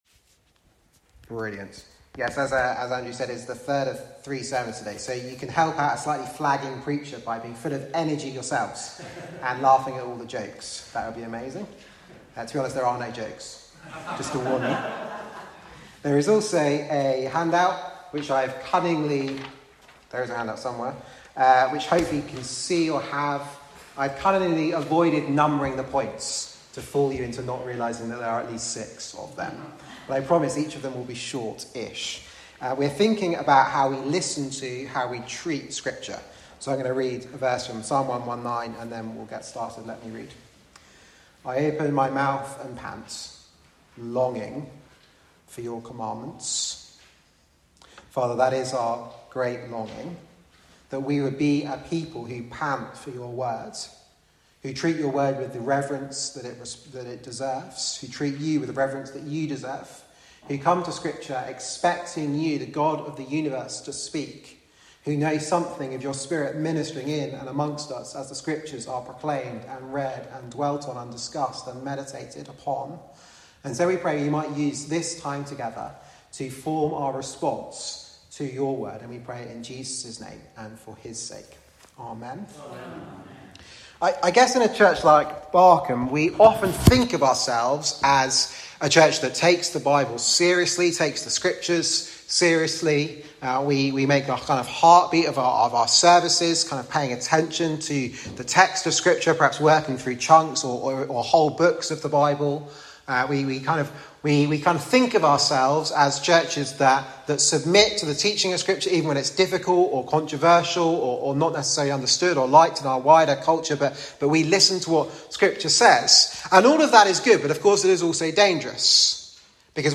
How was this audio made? Media for Sunday Evening